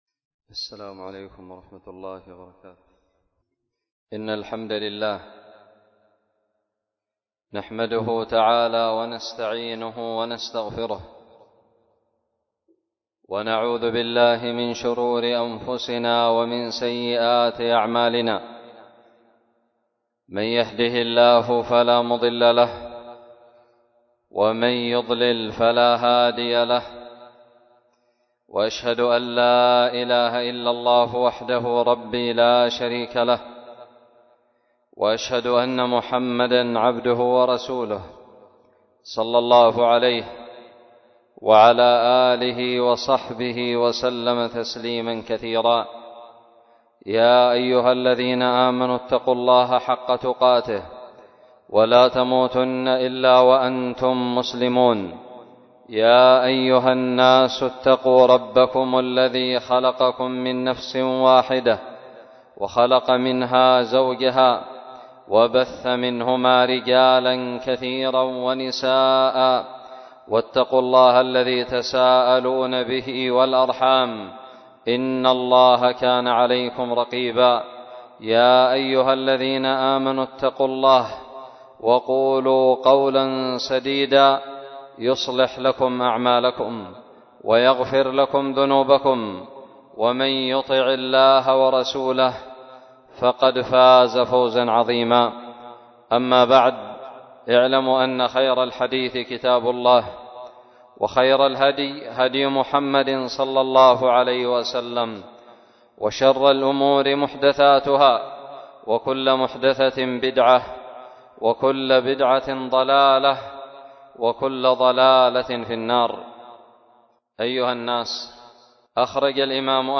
خطب الجمعة
ألقيت بدار الحديث السلفية للعلوم الشرعية بالضالع في 6 جمادى الأولى 1443هـ